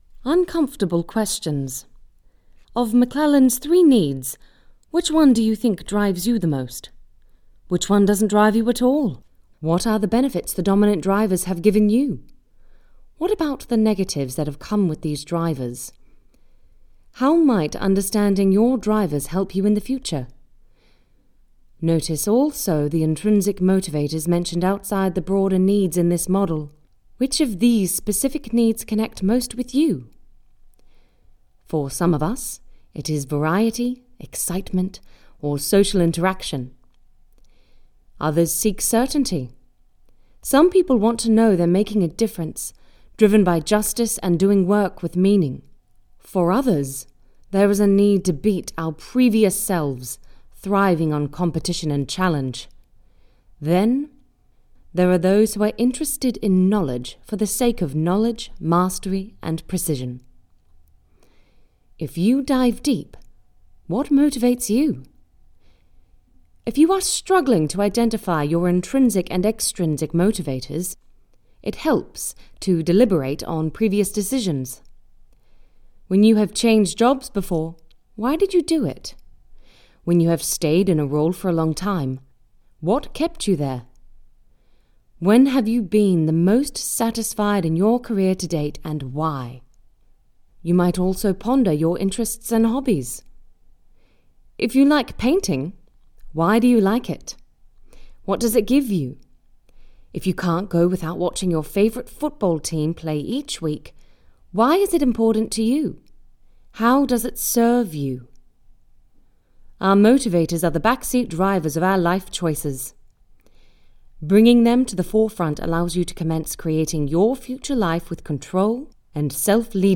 Female
Warm, buttery, smooth voice with ample capability to energise and deliver bright reads too. Great skill in delivering with a conversational tone to a more intimate read.
Audiobooks
British Accented Book Read
All our voice actors have professional broadcast quality recording studios.
0510Standard_British_Book_Read.mp3